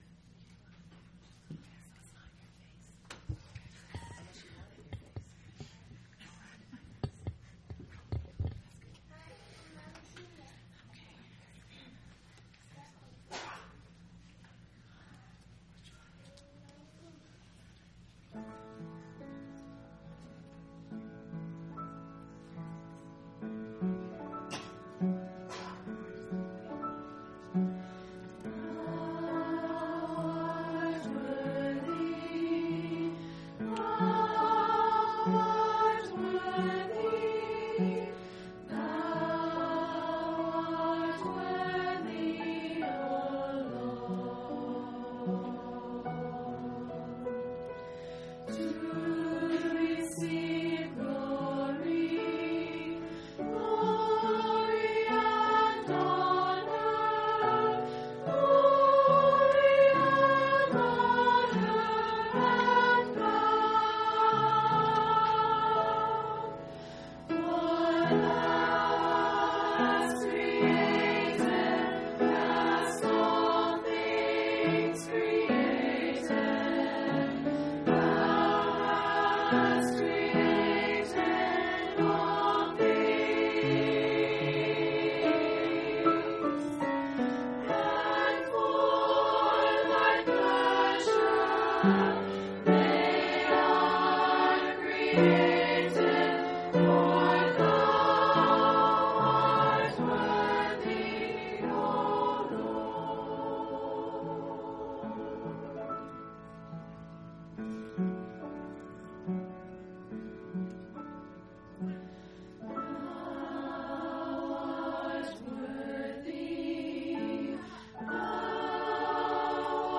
5/15/2005 Location: Phoenix Local Event